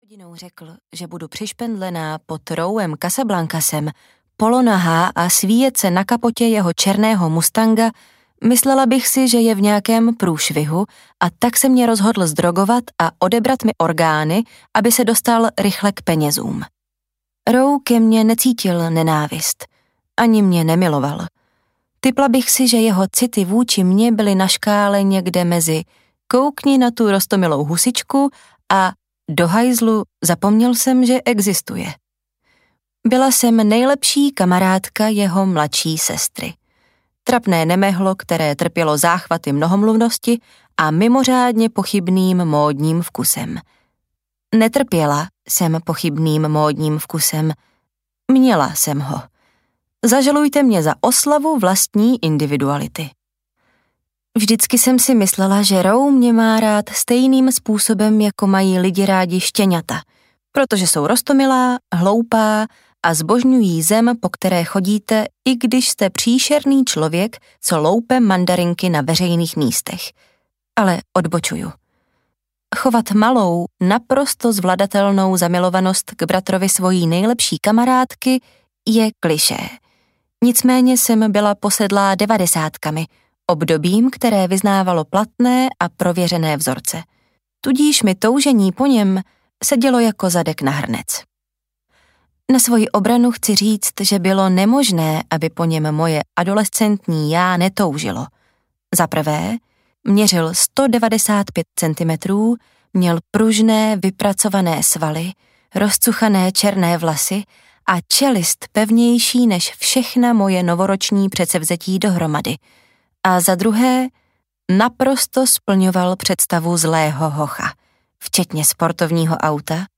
Opravdu šíleně hluboce audiokniha
Ukázka z knihy